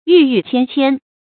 郁郁芊芊 注音： ㄧㄩˋ ㄧㄩˋ ㄑㄧㄢ ㄑㄧㄢ 讀音讀法： 意思解釋： ①猶言郁郁蔥蔥。